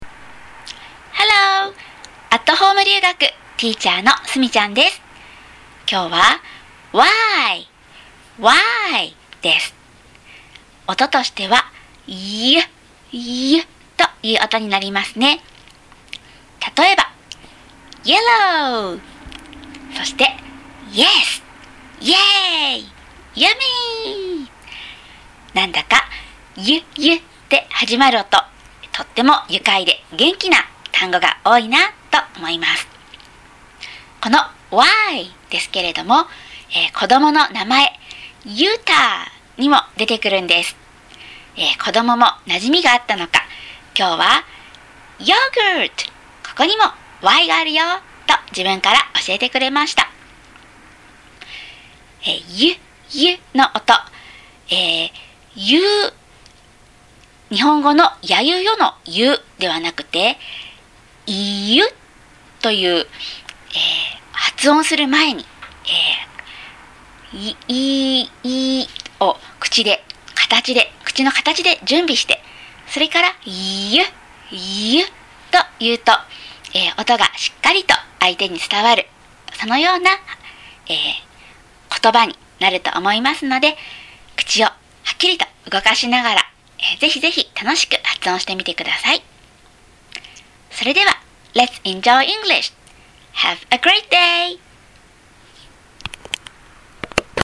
音声メッセージでもお話ししています。